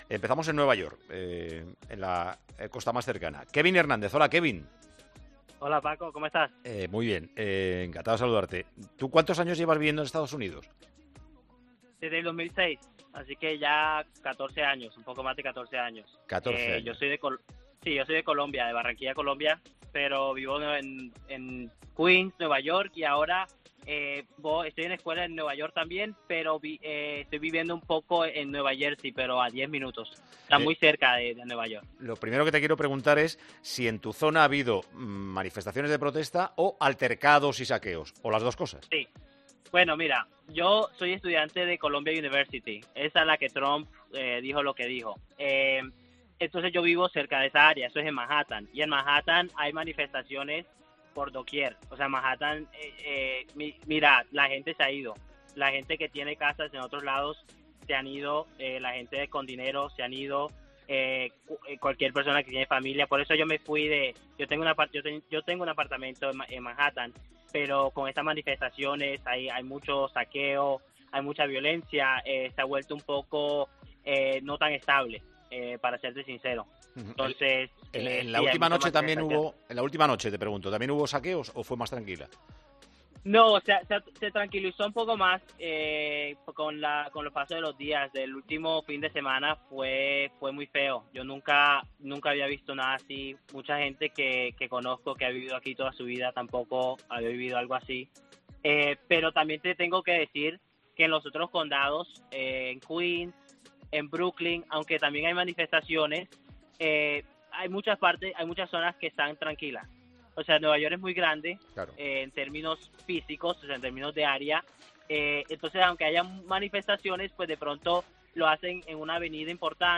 AUDIO: Hablamos con tres oyentes de Tiempo de Juego en Nueva York, Houston y San Francisco para conocer cómo están viviendo las protestas contra el racismo.